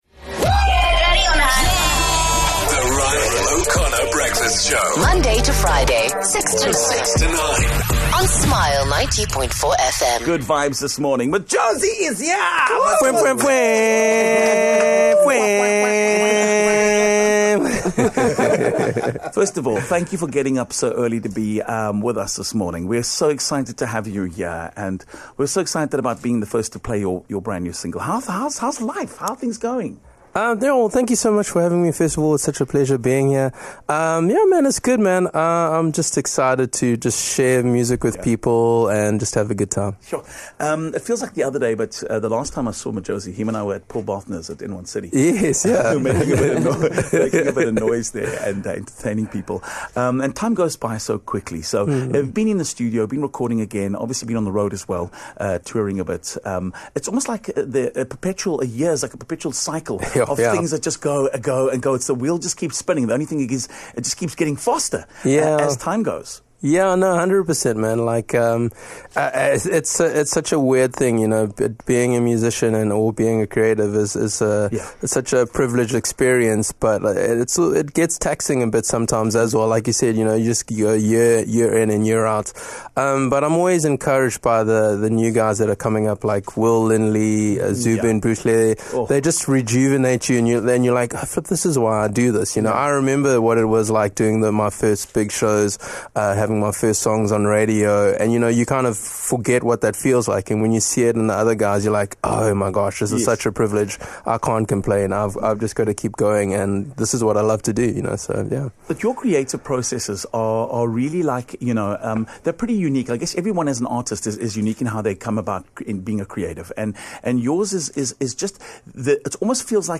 He popped in for a chat.